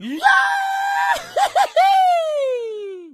PigLaugh 01.wav